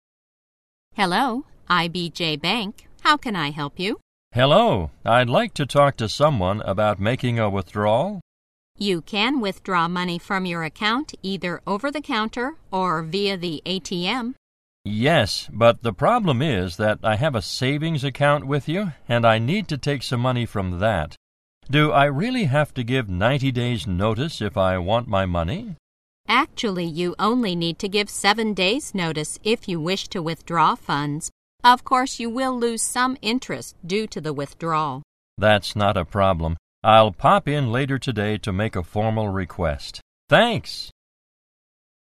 在线英语听力室银行英语情景口语 第14期:现金业务 预支情景(2)的听力文件下载, 《银行英语情景口语对话》,主要内容有银行英语情景口语对话、银行英语口语、银行英语词汇等内容。